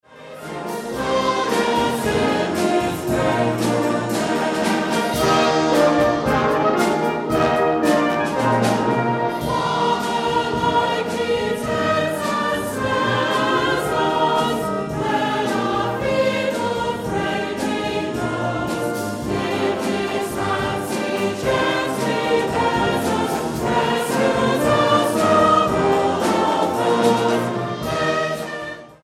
STYLE: Hymnody